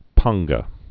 (pänggə, pä-äng-)